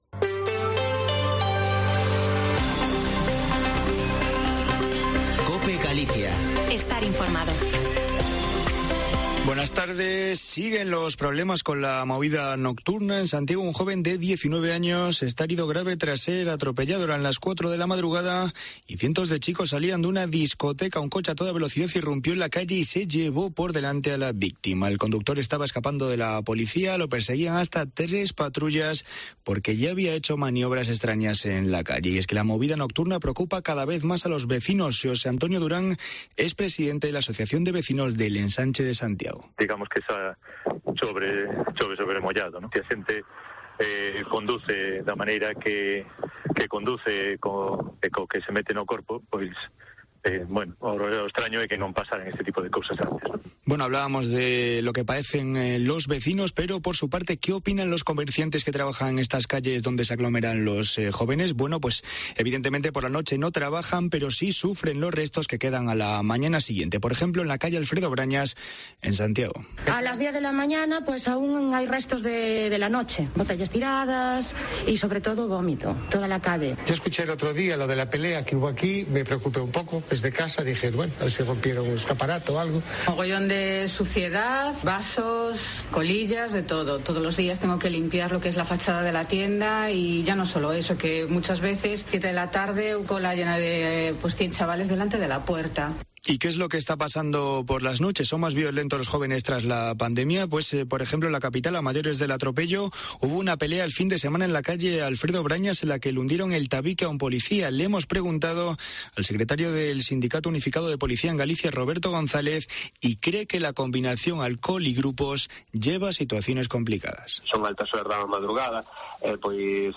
Informativo Mediodía en Cope Galicia 04/10/2021. De 14.48 a 14.58h